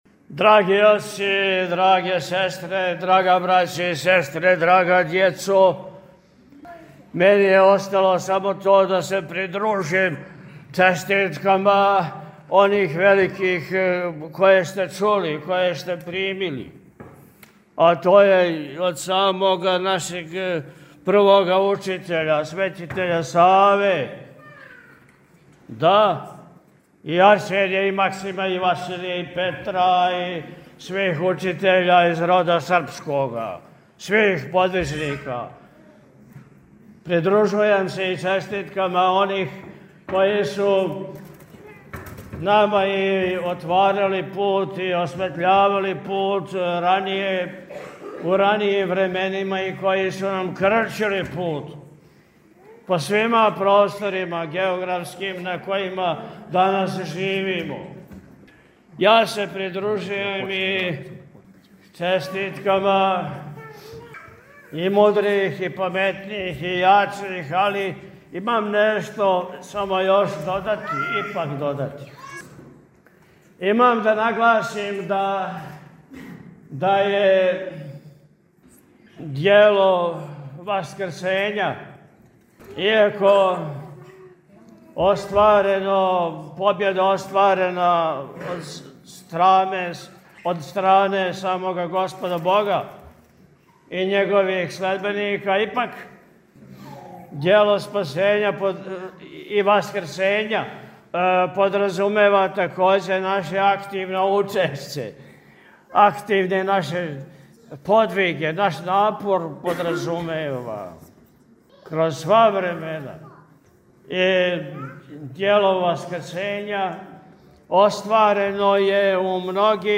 На Светло Христово Васкрсење, у недељу 12. априла 2026. године, Његово Високопреосвештенство Архиепископ и Митрополит милешевски г. Атанасије служио је свечану Васкршњу Литургију у Вазнесењском [...]
Беседу Митрополита Атанасија можете послушати овде: